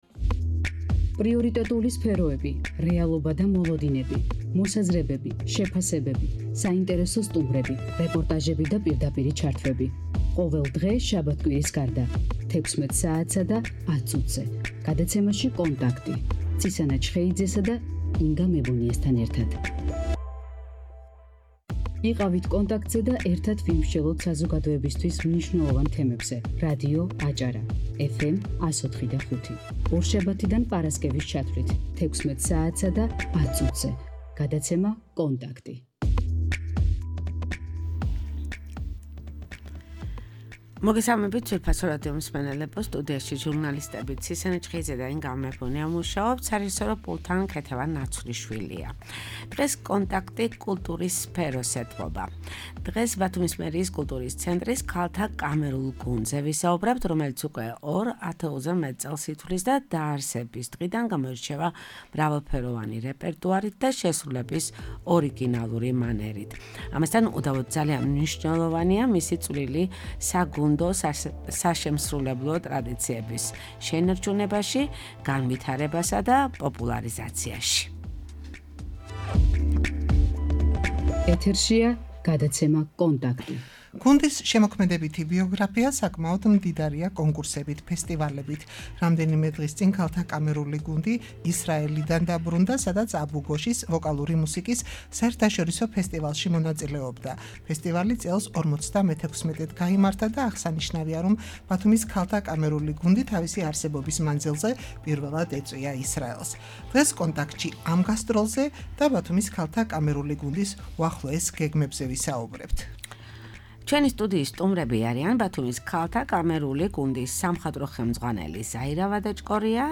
ბათუმის ქალთა კამერული გუნდი